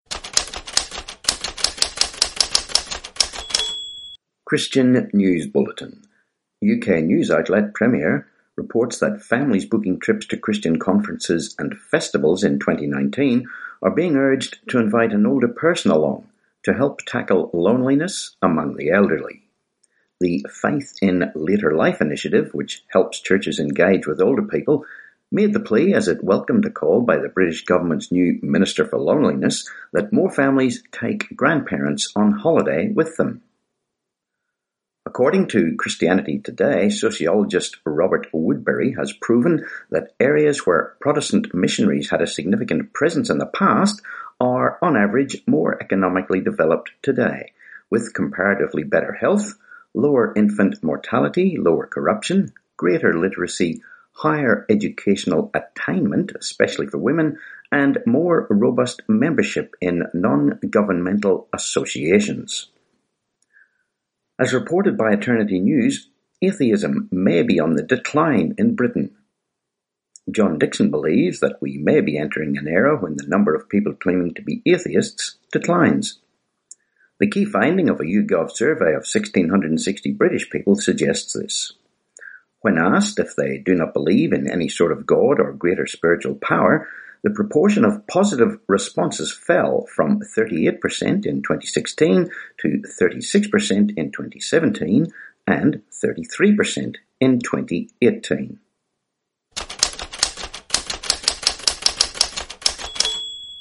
13Jan19 Christian News Bulletin